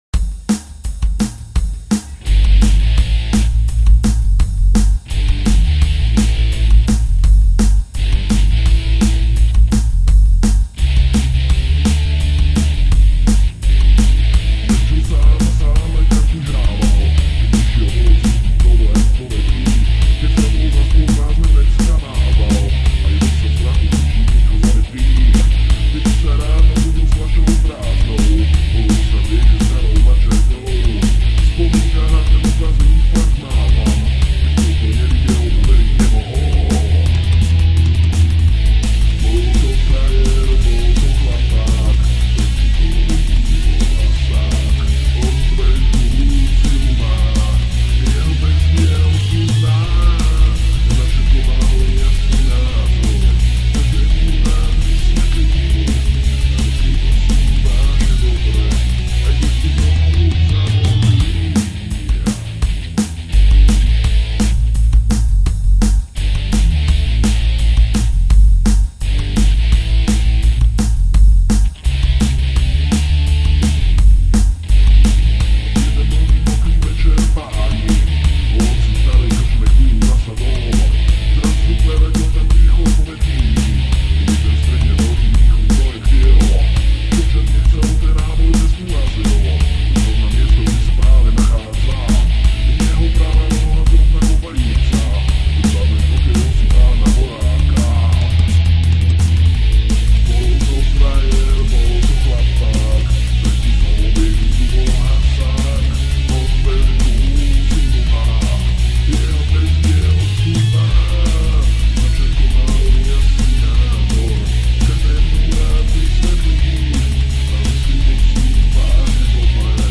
Aj som rozmyslal, ze ju nahram znova, ked uz mi zvukovka neoneskoruje ale sa mi asi nechce.
No vidime, ze ten song1 ma zly zvuk aj pri lepsej kvalite.